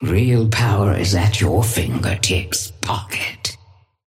Sapphire Flame voice line - Real power is at your fingertips, Pocket.
Patron_female_ally_synth_start_03.mp3